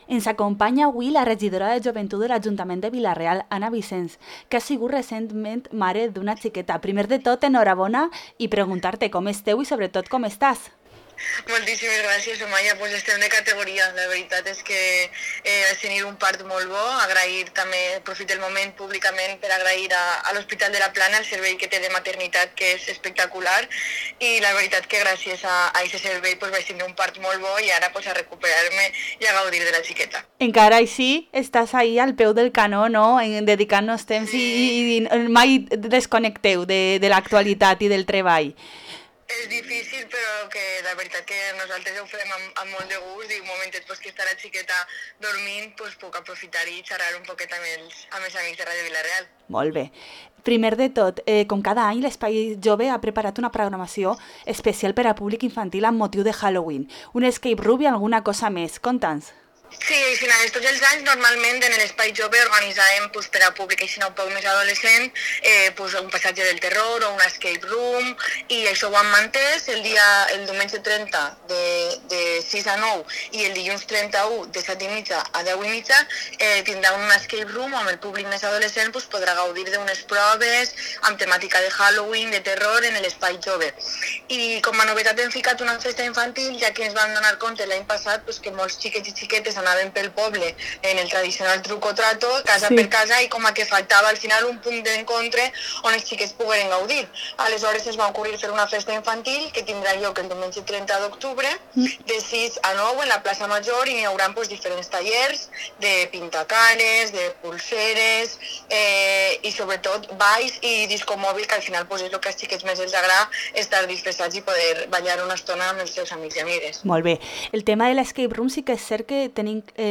Anna Vicens, regidora de Joventut de Vila-real, ens conta la programació per Halloween